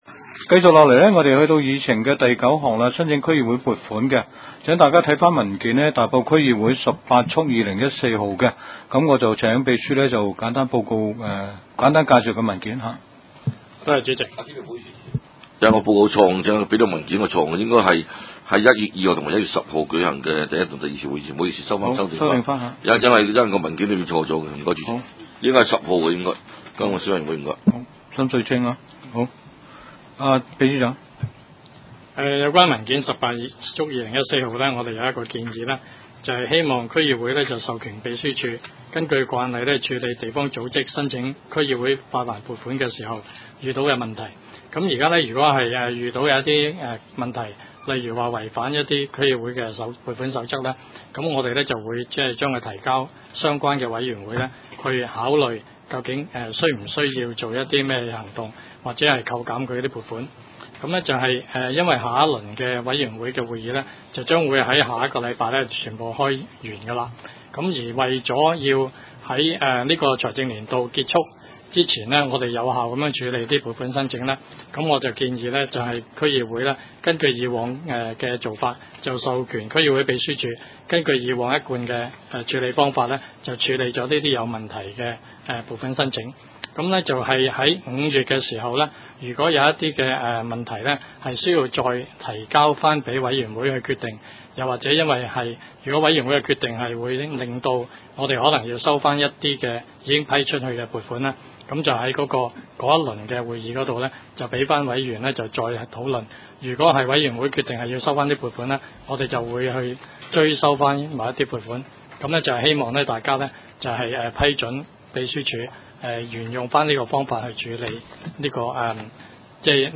区议会大会的录音记录
大埔区议会秘书处会议室